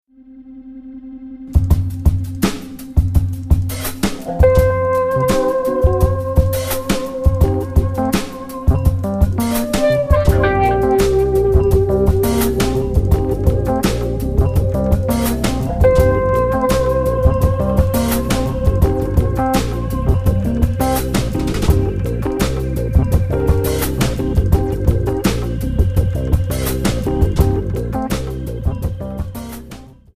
Alternative,Blues